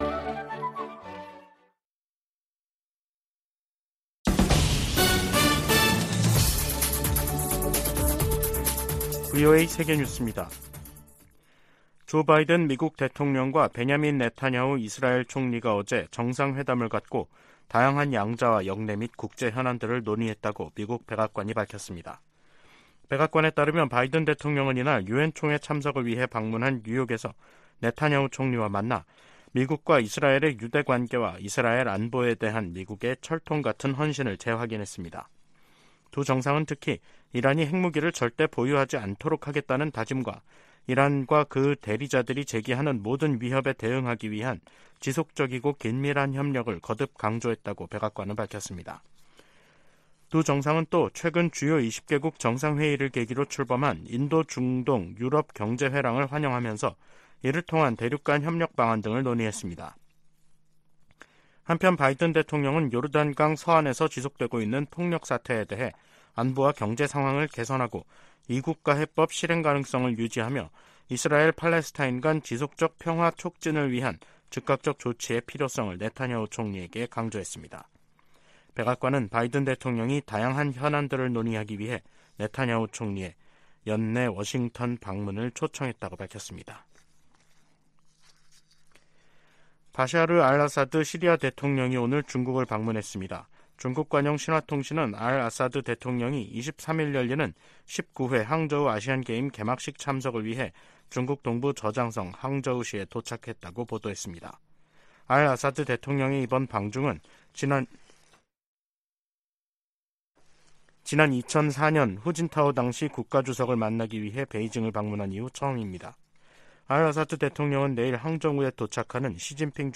VOA 한국어 간판 뉴스 프로그램 '뉴스 투데이', 2023년 9월 21일 2부 방송입니다. 윤석열 한국 대통령이 유엔총회 연설에서 북한의 핵과 탄도미사일 개발이 세계 평화에 대한 중대한 도전이라고 규탄했습니다.